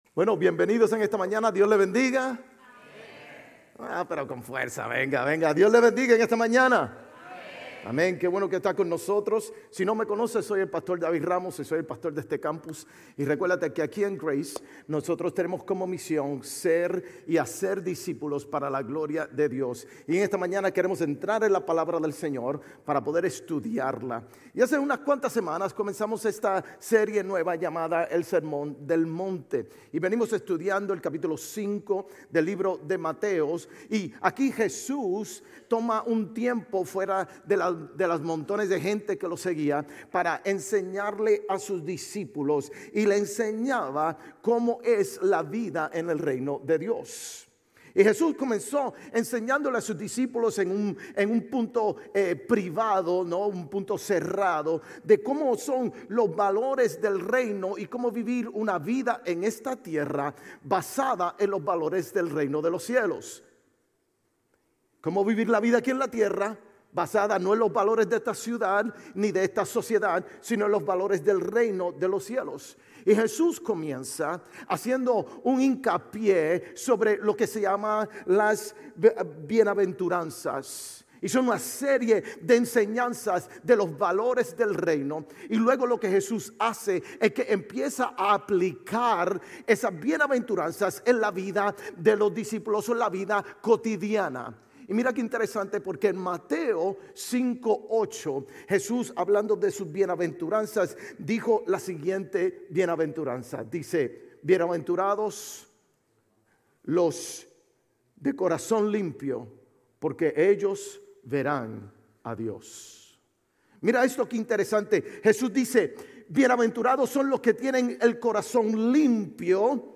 Grace Community Church Dover Campus Sermons 2_23 Grace Español Campus Feb 23 2025 | 00:38:02 1x 00:00 / 00:38:02 Subscribe Share RSS Feed Share Link Embed